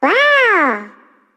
One of Yoshi's voice clips in Mario Party 6